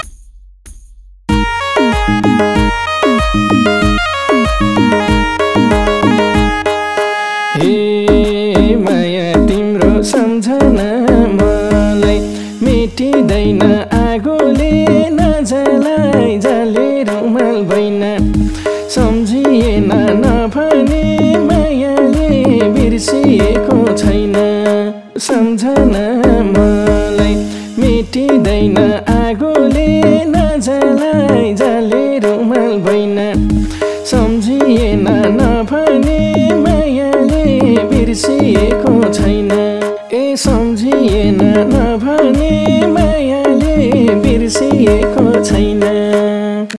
SOLO SINGING TUNE TRACKS (एकल गायन तर्फ) Singing View